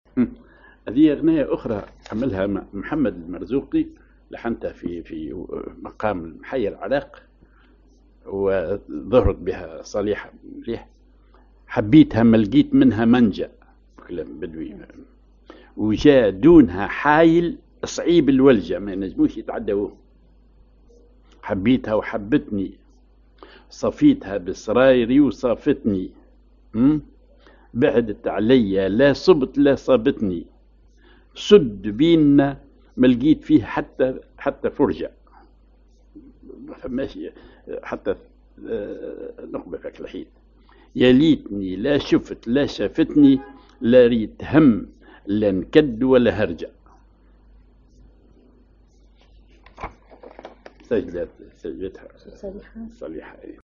ar محير العراق
ar الوحدة
أغنية